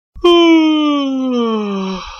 Yawn.ogg